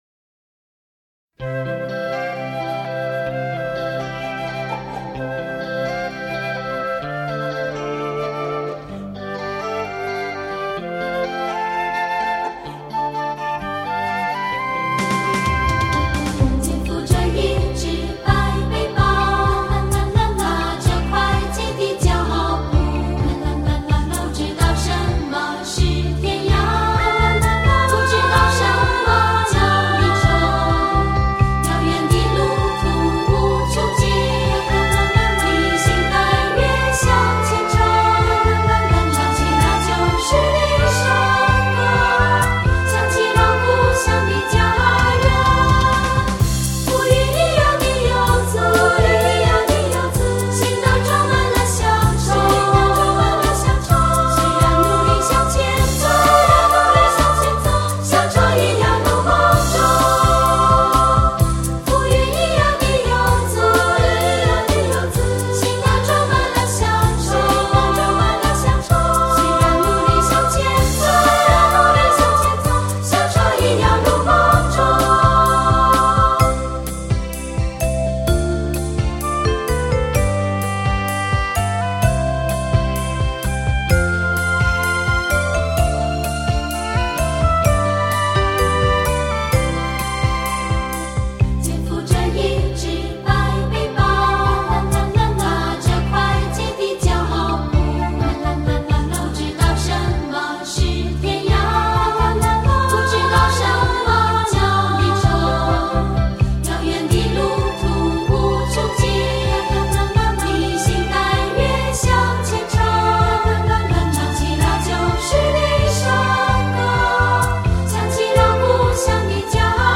以清澈优美的和声